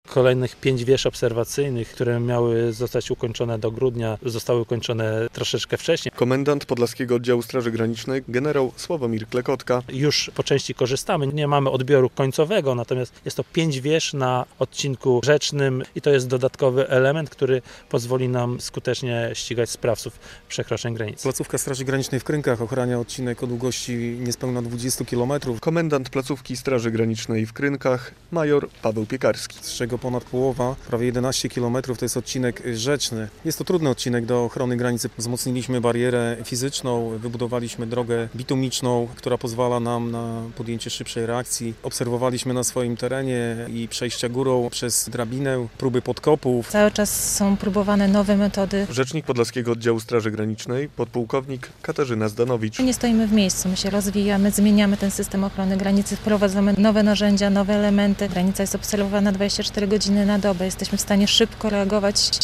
Straż Graniczna modernizuje barierę na granicy z Białorusią - relacja